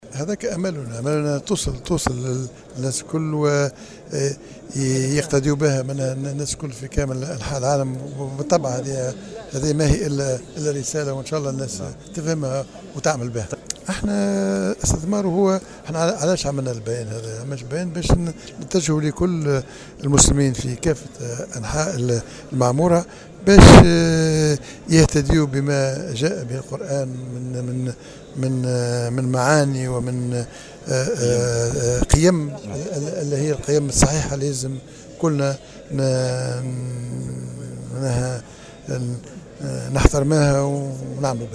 وعبر رئيس الحكومة الحبيب الصيد الذي أشرف على التظاهرة، في تصريح لمراسل الجوهرة أف أم، عن أمله في أن تصل معاني هذا البيان ومقاصده إلى جميع المسلمين في كافة أرجاء العالم كي تكون لهم قدوة، ولكي يهتدوا بما جاء به القرآن الكريم من معاني وقيم وجب احترامها والعمل بها وفق تعبيره.